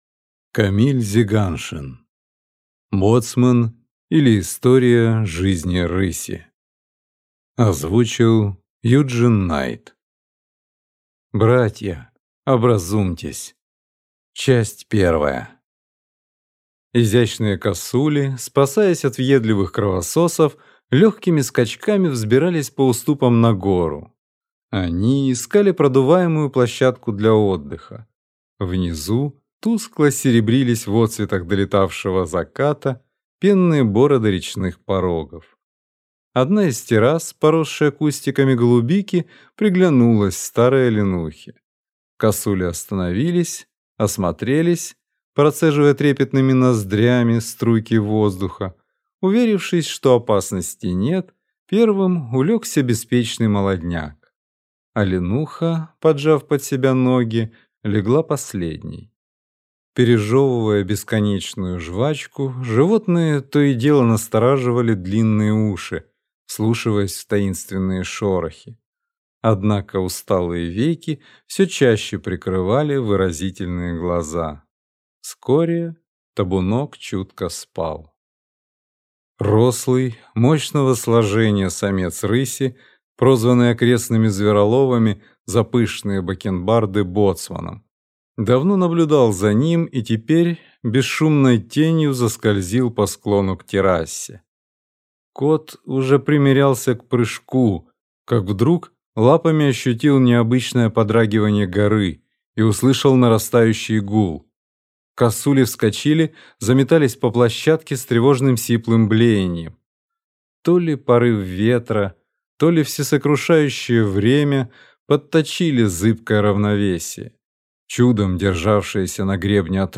Аудиокнига Боцман, или История жизни рыси | Библиотека аудиокниг